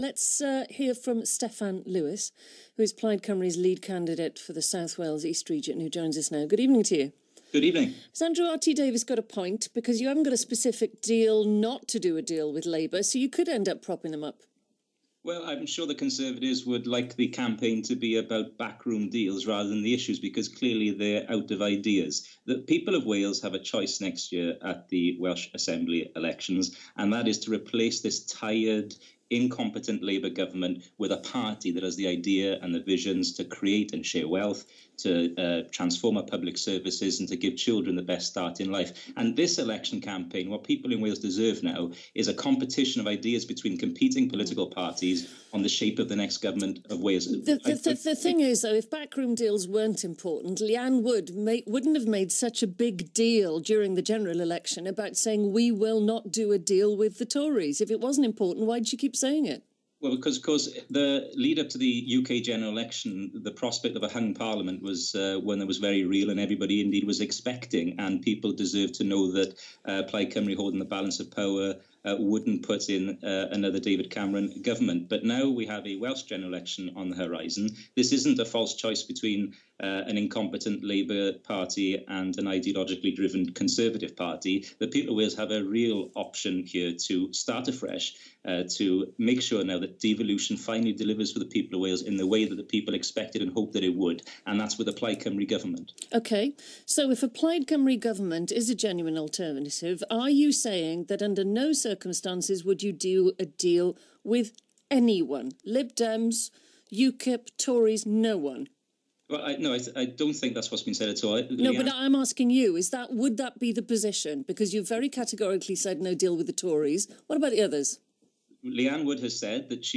Steffan Lewis interview on BBC Radio Wales